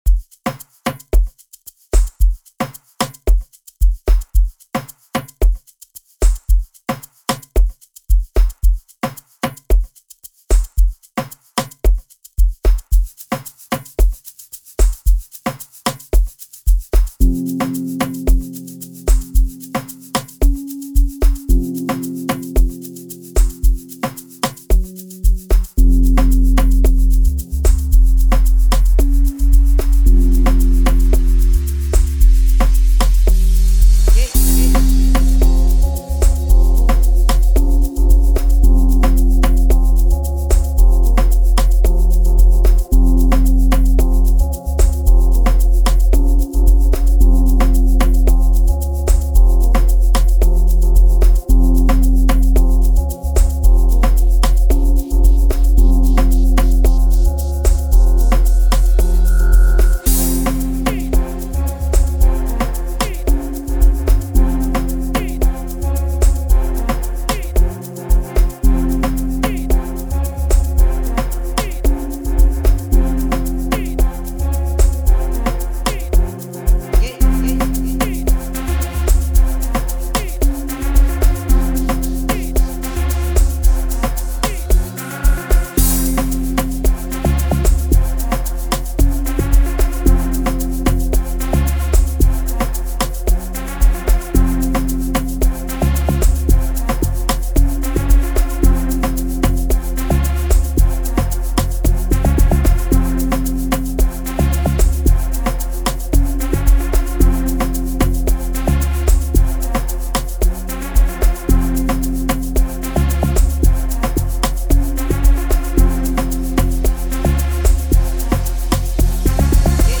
07:42 Genre : Amapiano Size